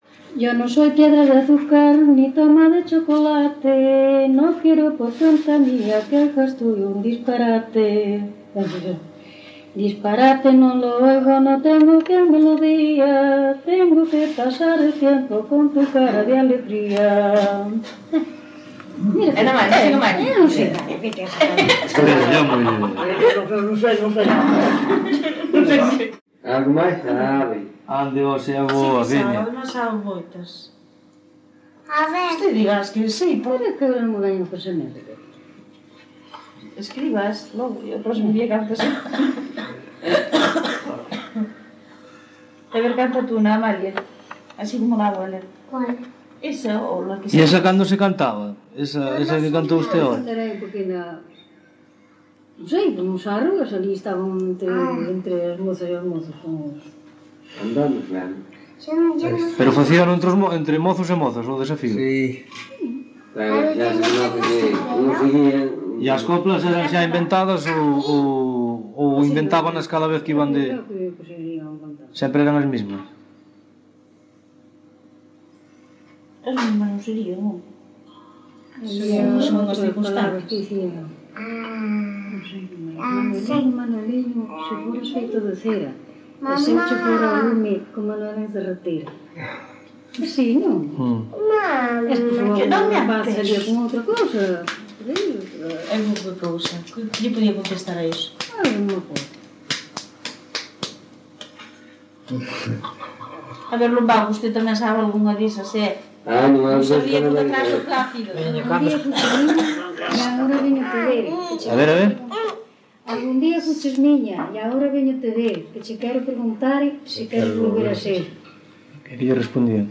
Palabras chave: desafío coplas
Tipo de rexistro: Musical
Soporte orixinal: Casete
Instrumentación: Voz
Instrumentos: Voz feminina, Voz masculina